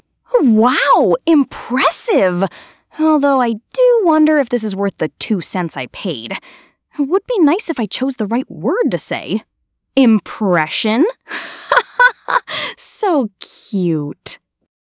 Google released Gemini 3.1 Flash TTS today, a new text-to-speech model that can be directed using prompts.
This looks like a pretty good TTS system.
gemini-speech.wav